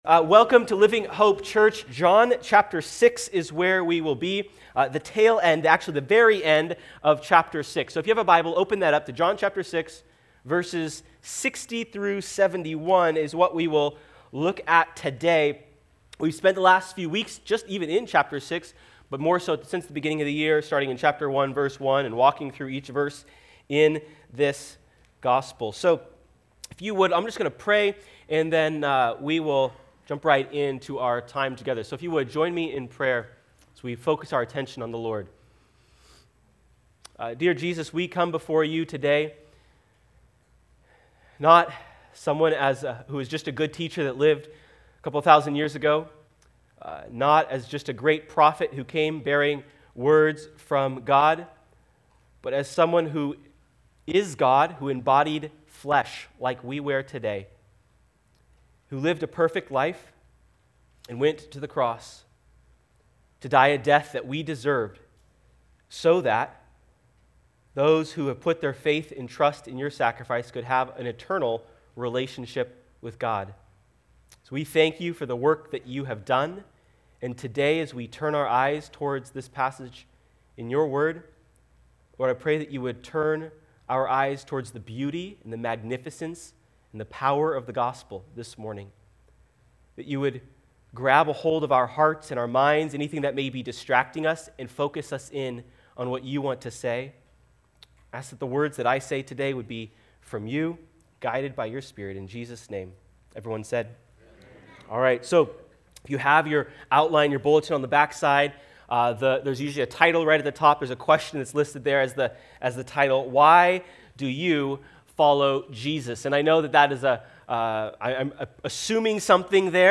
Join us as we explore the many reasons people follow Jesus or don't follow him, and what it should look like to truly follow Him. Sermon Notes:Coming soon.